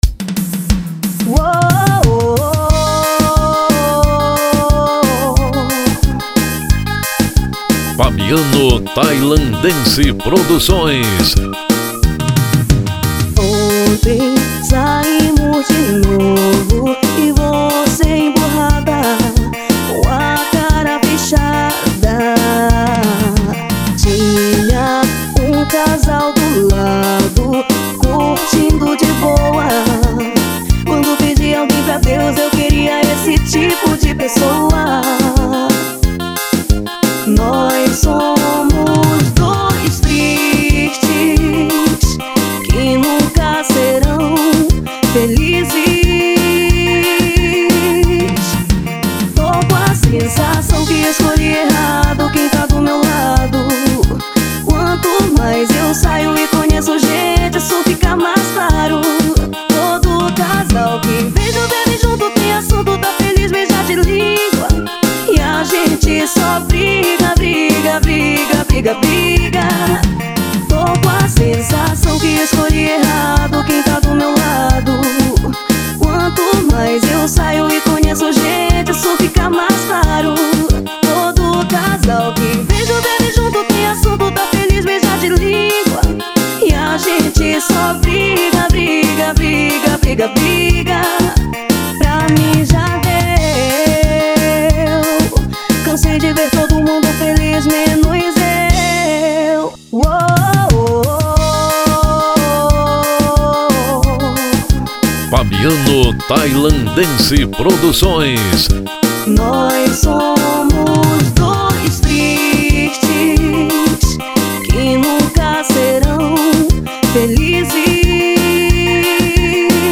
OUÇA NO YOUTUBE Labels: Forrozinho Facebook Twitter